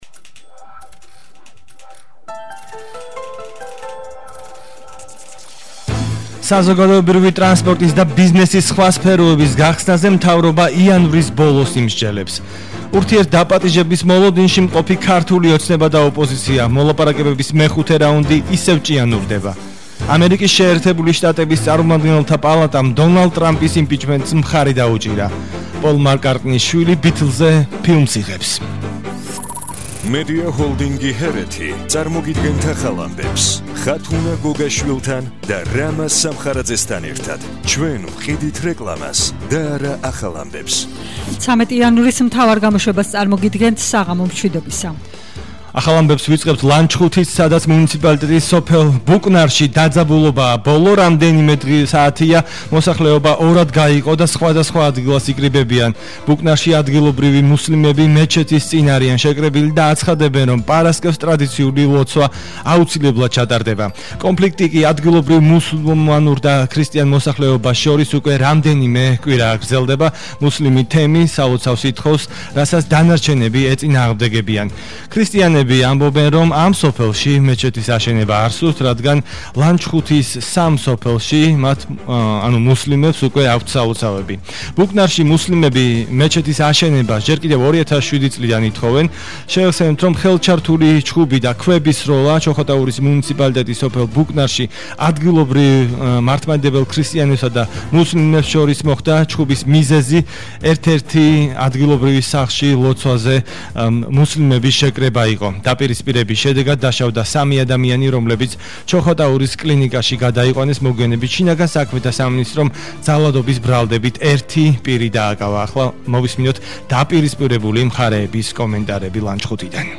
მთავარი საინფორმაციო გამოშვება –13/01/21 – HeretiFM